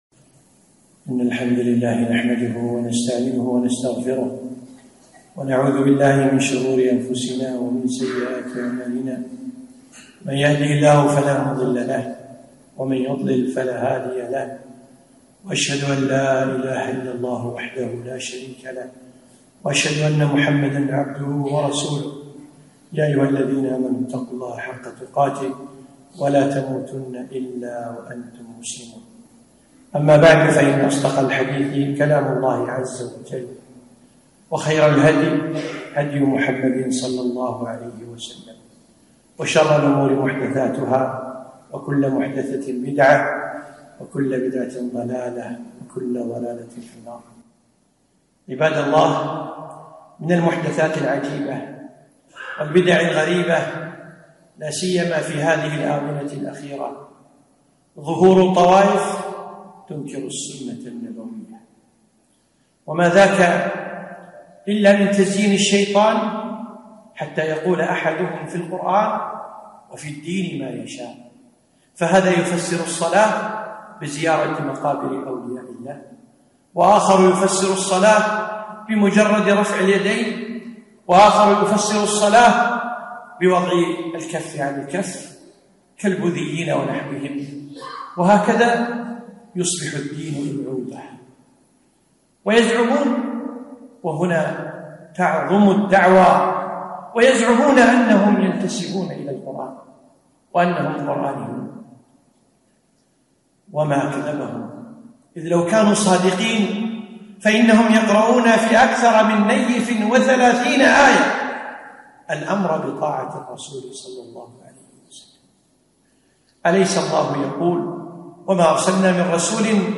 خطبة - السنة نجاة